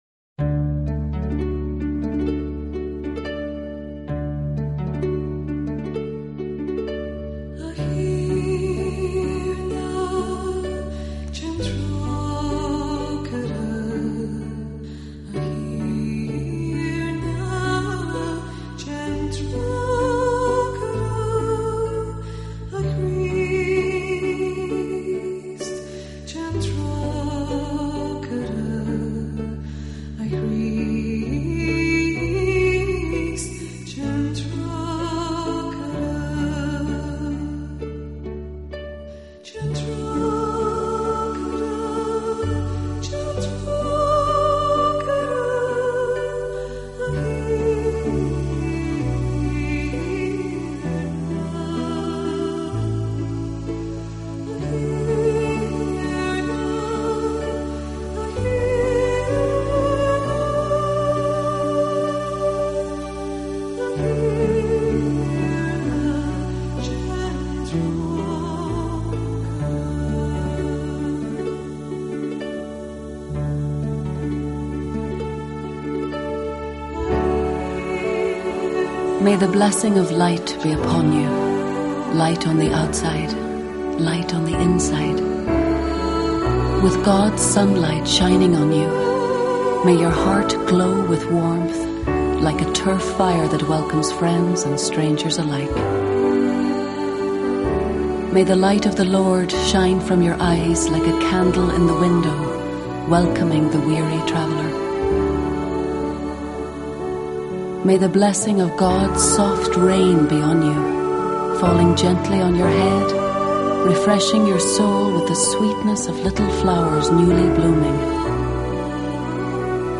2005年爱尔兰圣诞音乐合辑
专辑主要以娓娓道来的配乐女声朗诵为主，若还想听优美旋律、浪漫女声。